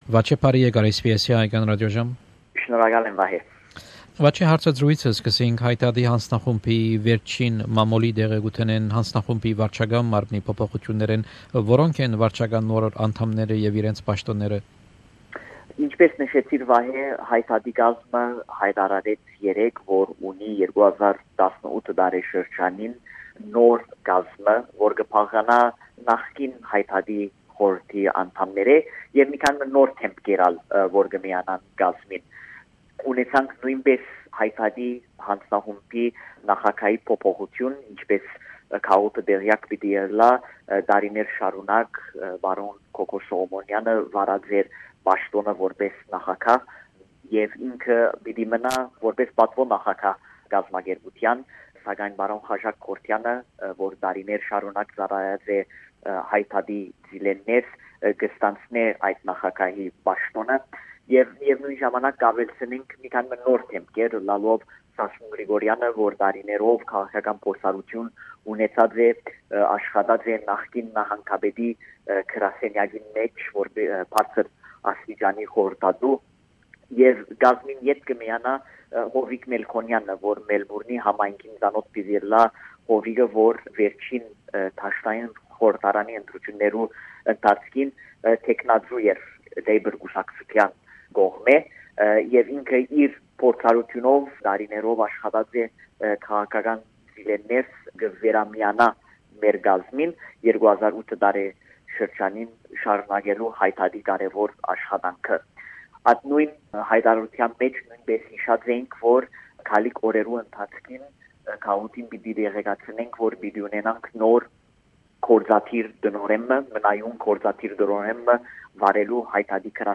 Հարցազրոյց